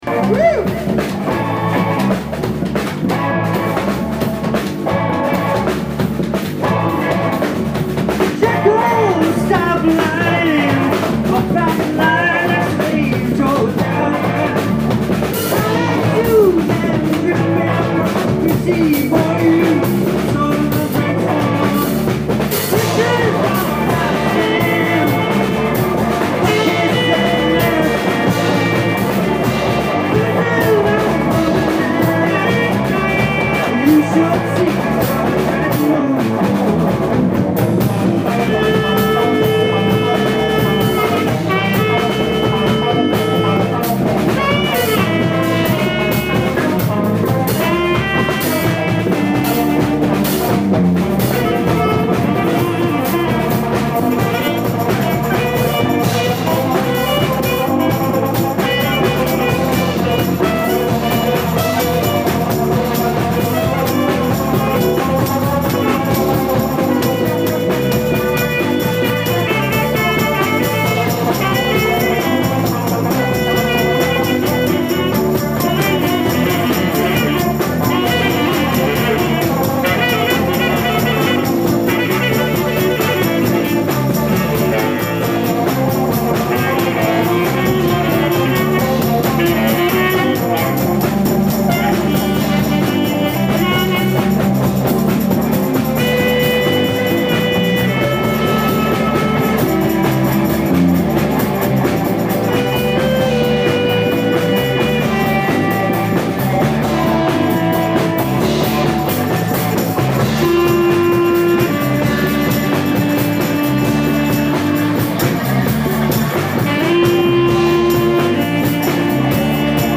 ALL MUSIC IS IMPROVISED ON SITE
voice/guitar
triangle/keys
tenor sax
drums
bass